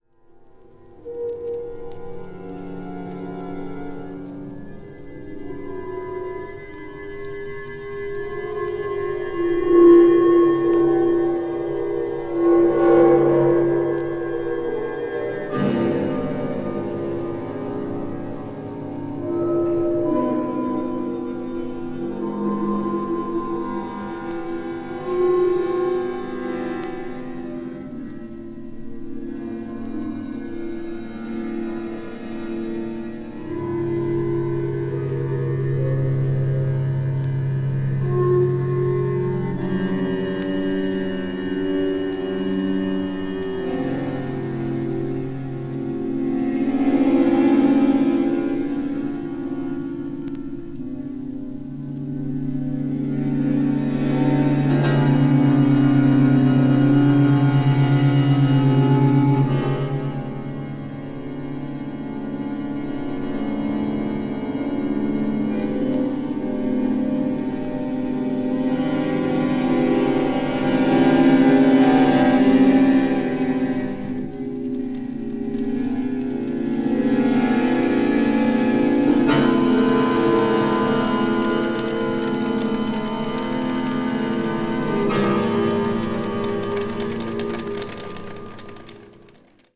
media : EX/EX(わずかにチリノイズが入る箇所あり)
臨場感あふれるライヴ録音。